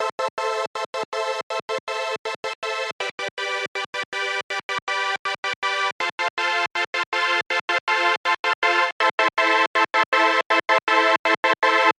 标签： 80 bpm Chill Out Loops Synth Loops 2.02 MB wav Key : A